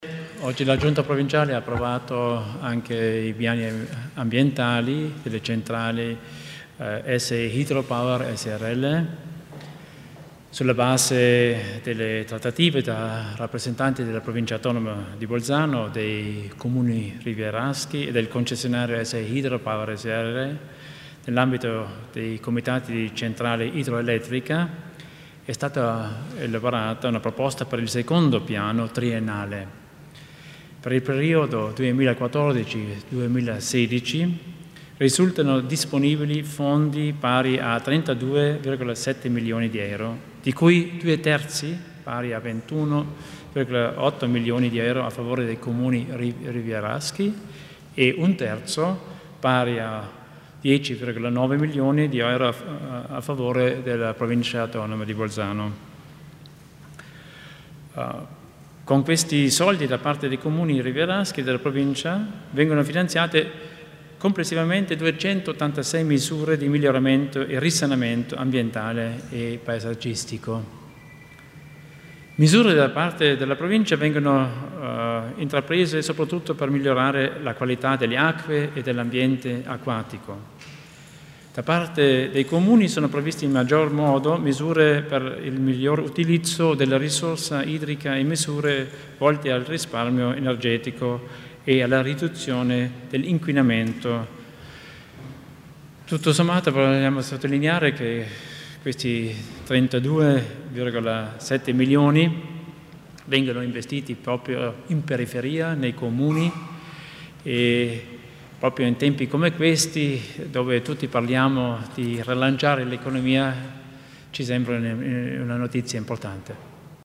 L'Assessore Theiner spiega i dettagli degli investimenti ambientali legati alle centrale idroelettriche